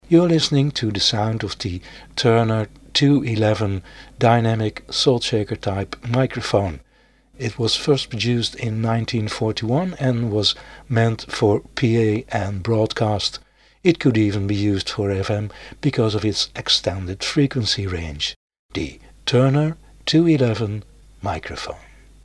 Facing a sound source, it was somewhat directional for the higher frequencies, if tilted upwards it had a more true omni directional pattern.
The 211 had a very rugged design, and, with an improved magnet structure and acoustic network, to extend the high frequency range and raise the extreme lows, it recorded high fidelity sound from 30 to 10.000 Hz.
A specially designed precision diaphragm resulted in unusually low harmonic and phase distortion, without sacrifice of high output level.
Turner 211 sound UK.mp3